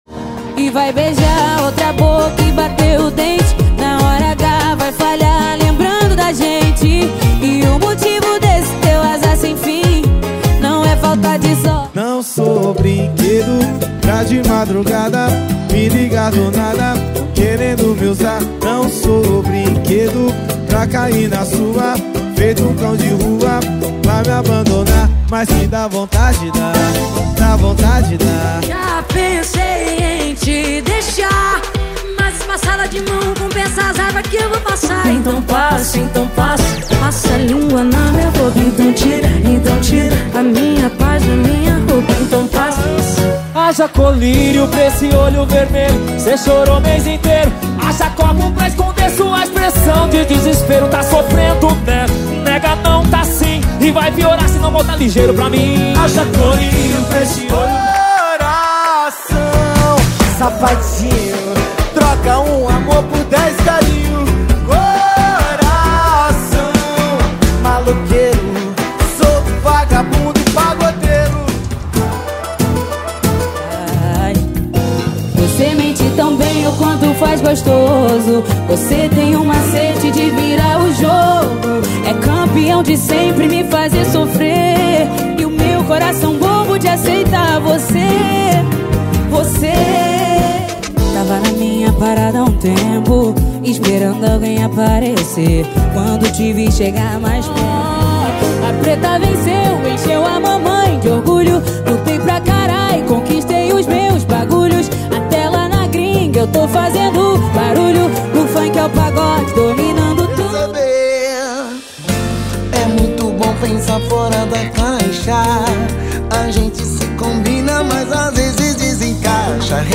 • Pagode e Samba = 50 Músicas